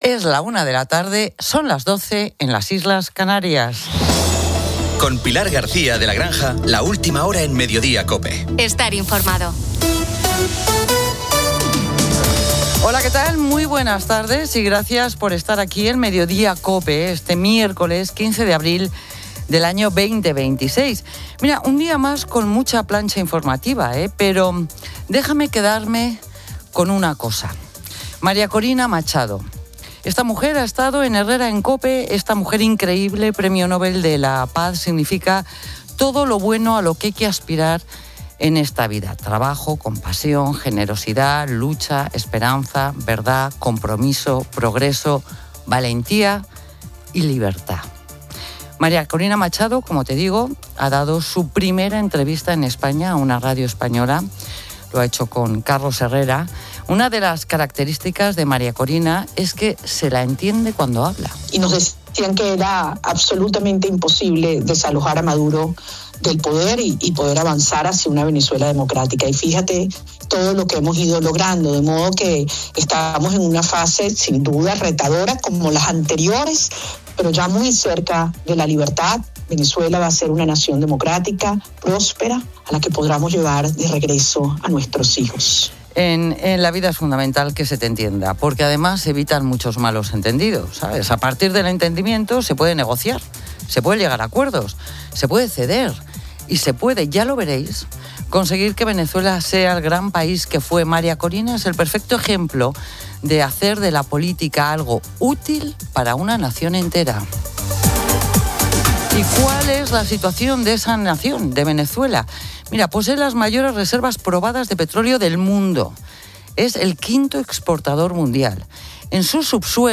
El programa presenta la primera entrevista en España de María Corina Machado, quien denuncia la devastadora economía de Venezuela bajo el comunismo y...